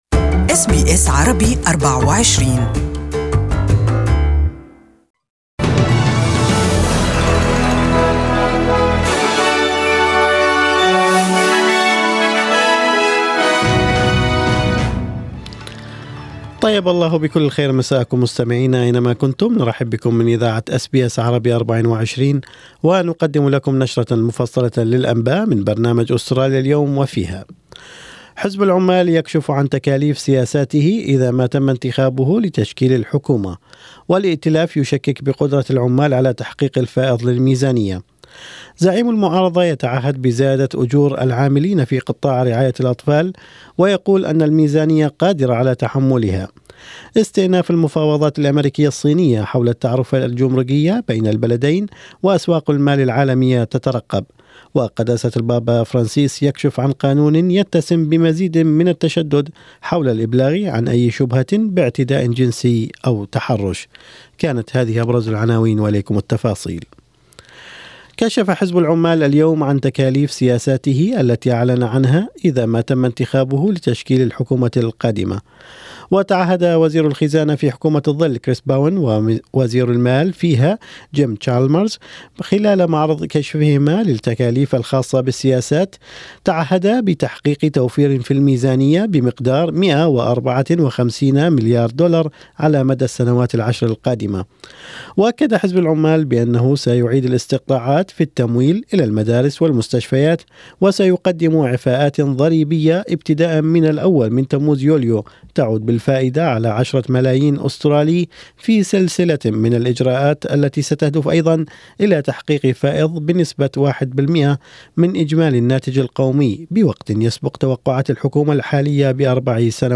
Evening News Bulletin: Labor's costings set to show budget savings of $154 billion over the next decade